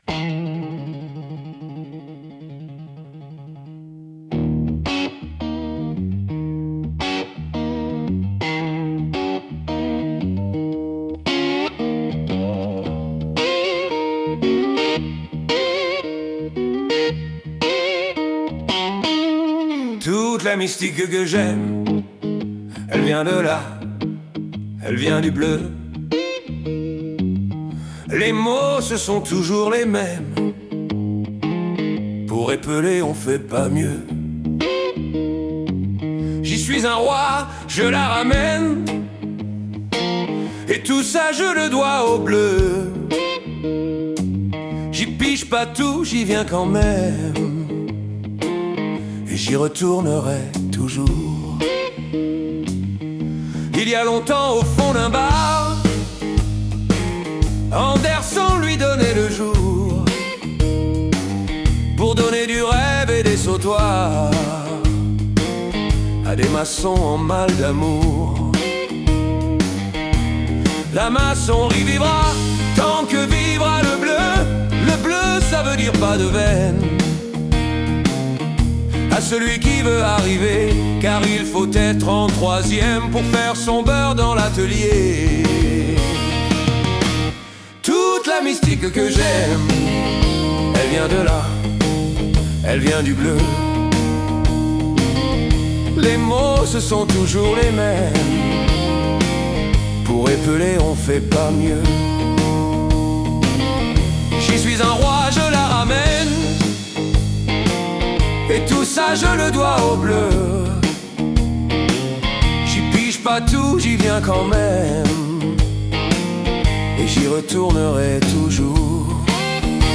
Enregistrement public